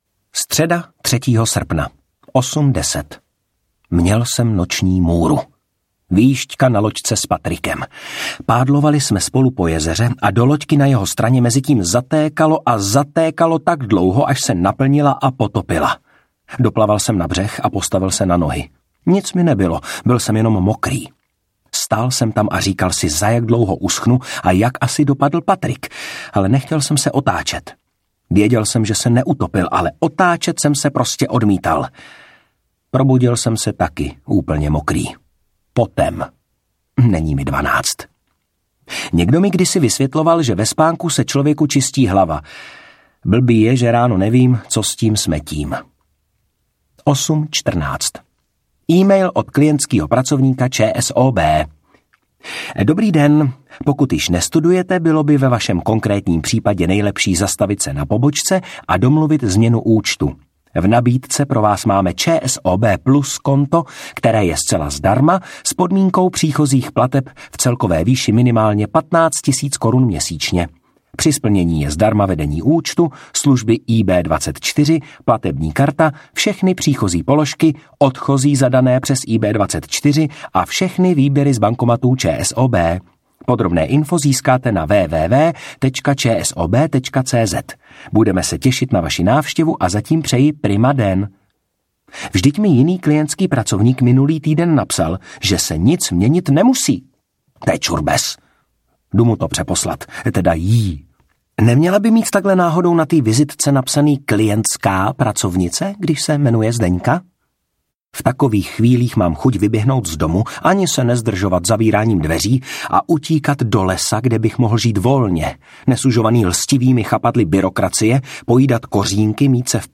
Dělám si to sám audiokniha
Ukázka z knihy